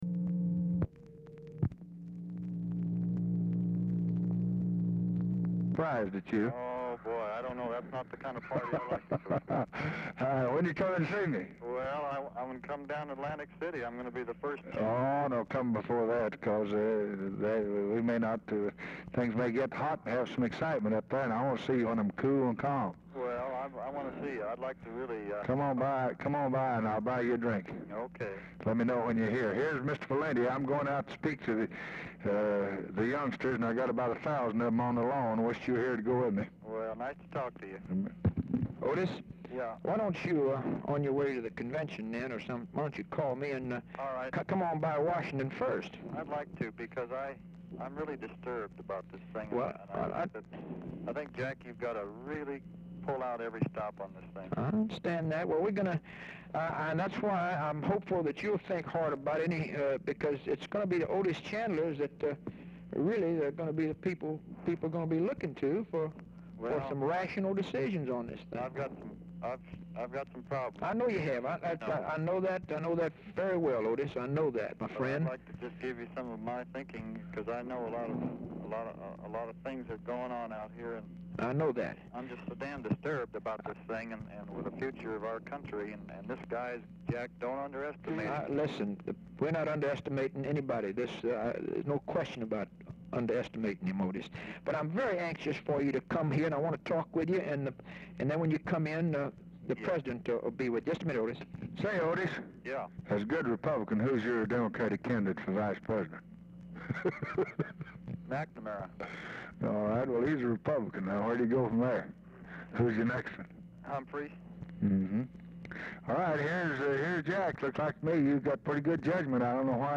Telephone conversation # 4282, sound recording, LBJ and OTIS CHANDLER, 7/20/1964, 7:05PM | Discover LBJ
Format Dictation belt
Location Of Speaker 1 Oval Office or unknown location
Specific Item Type Telephone conversation